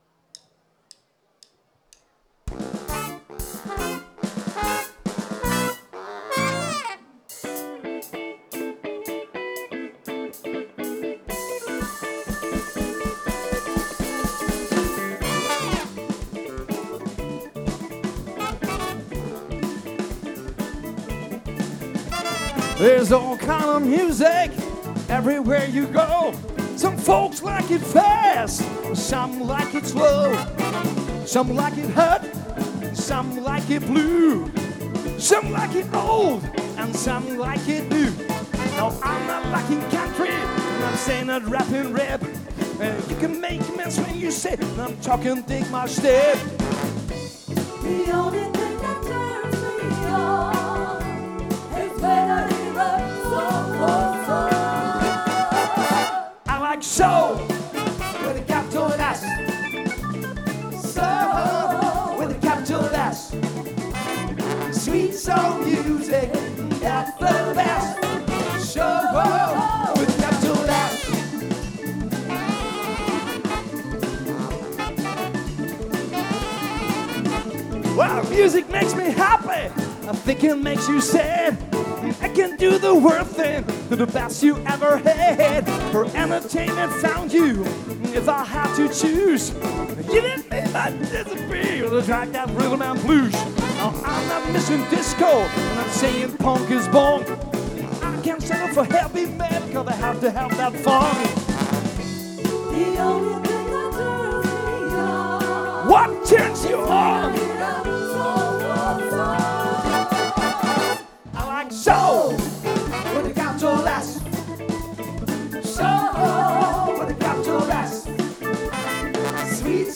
· Genre (Stil): Soul